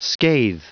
Prononciation du mot scathe en anglais (fichier audio)
Prononciation du mot : scathe